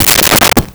Close Cabinet Door 02
Close Cabinet Door 02.wav